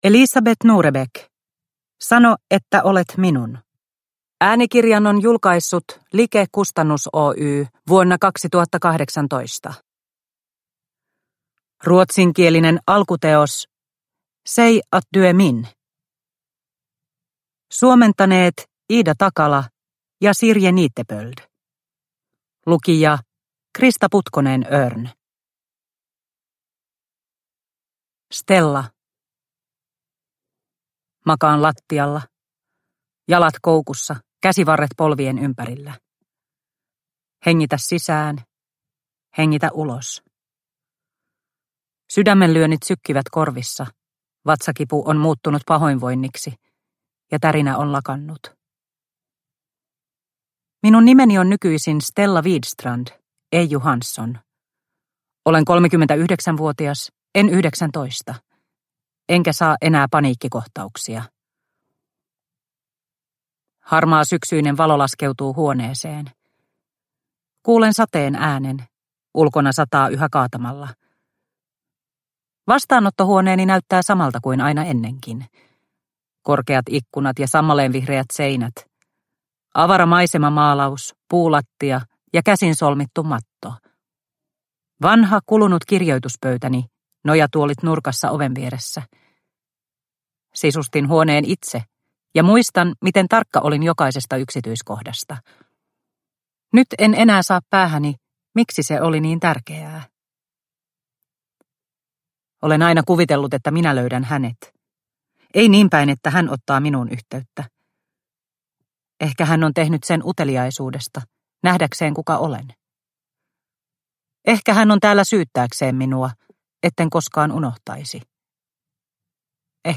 Sano että olet minun – Ljudbok – Laddas ner